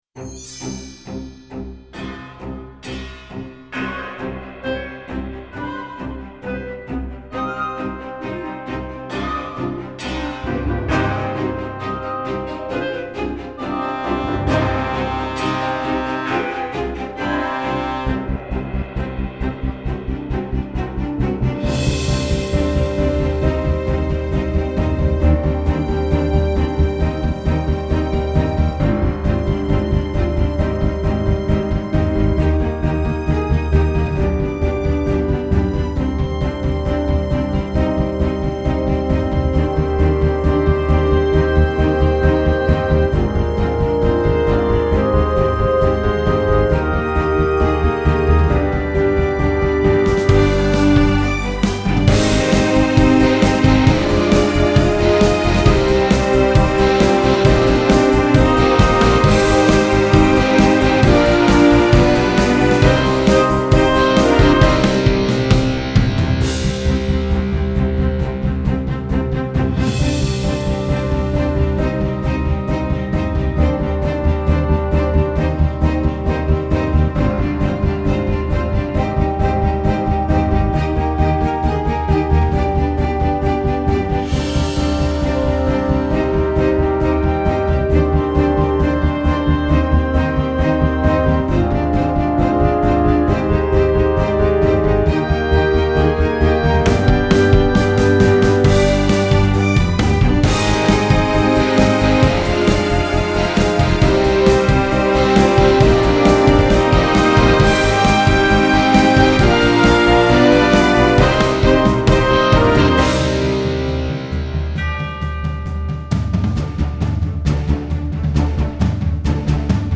Das Erwachen des Drachen ist eine NEUES MUSICAL, geschrieben für das
Ouverture